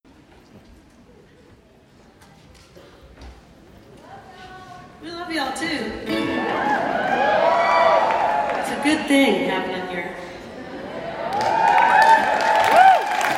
19. talking with the crowd (0:13)